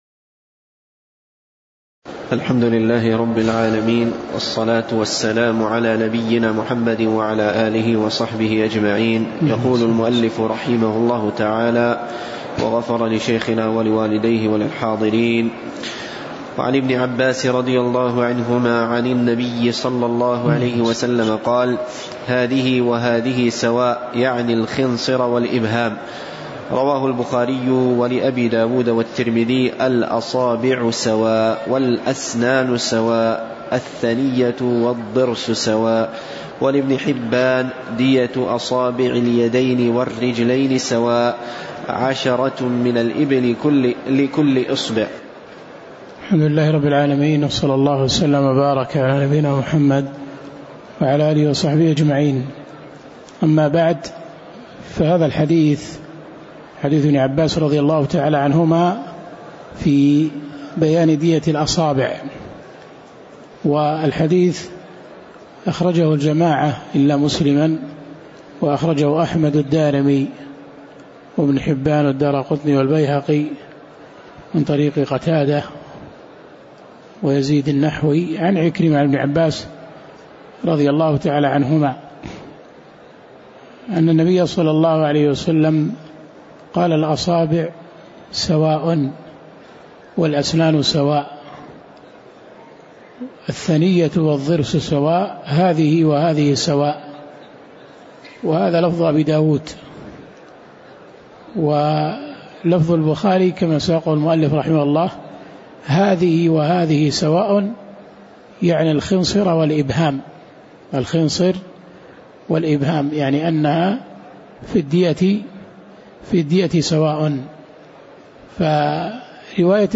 تاريخ النشر ١٠ رجب ١٤٣٩ هـ المكان: المسجد النبوي الشيخ